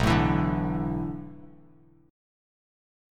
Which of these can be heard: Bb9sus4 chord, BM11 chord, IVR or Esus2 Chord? BM11 chord